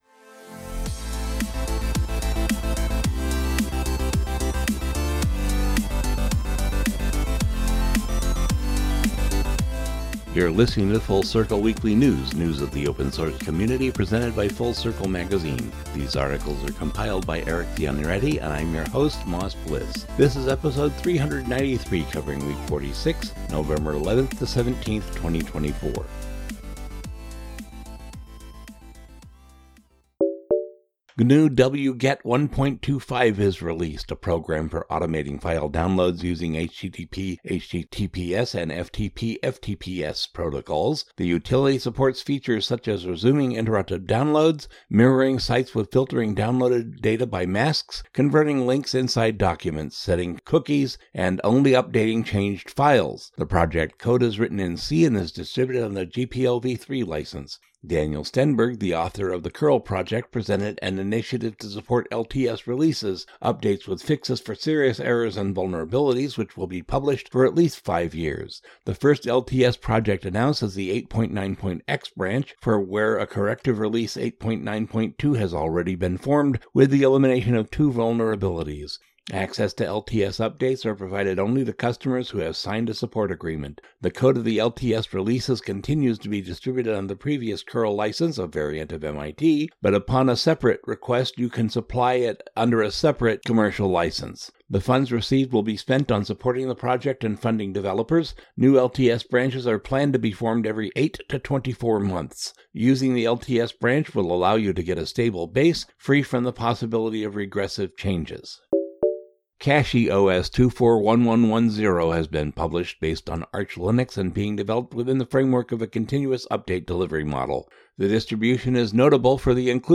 A short weekly podcast giving just the news, no chit-chat, no banter, just FOSS/Ubuntu/Linux news.